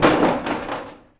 metal_sheet_impacts_16.wav